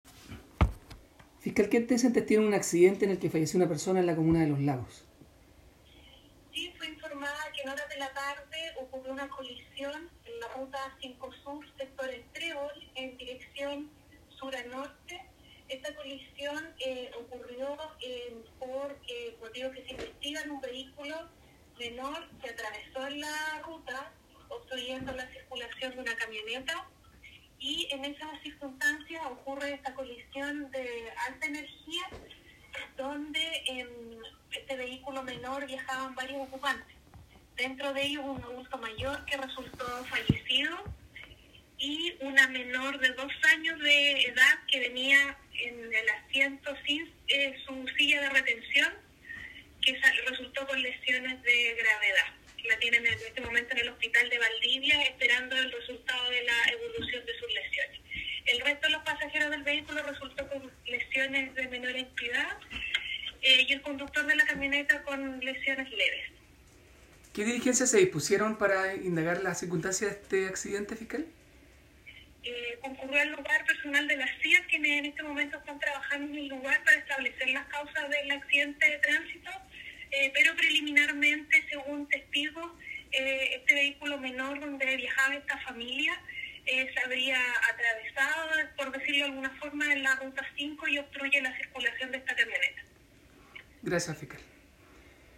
la fiscal jefe de Los Lagos, Claudia Baeza, indicó que un adulto mayor, ocupante del vehículo menor, falleció producto del impacto…..
FISCAL-LOS-LAGOS.m4a